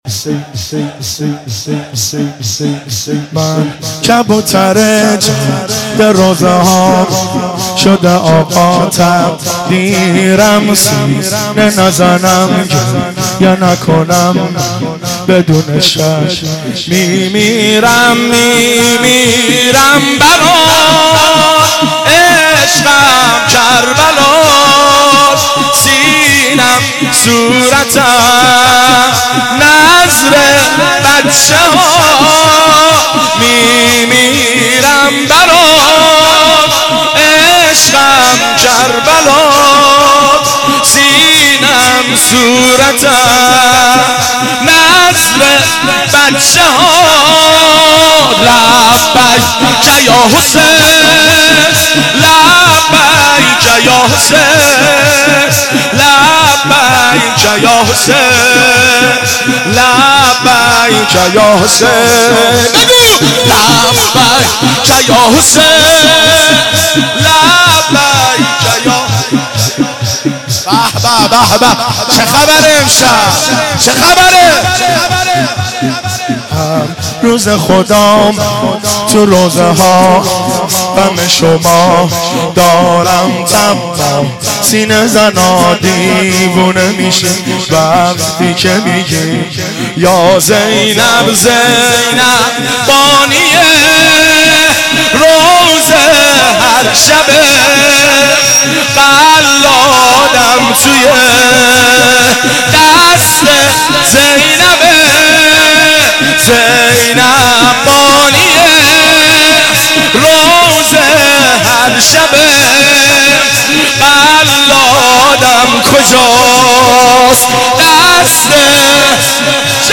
صوت مراسم شب نهم محرم (تاسوعا) ۱۴۳۷ هیئت غریب مدینه امیرکلا ذیلاً می‌آید: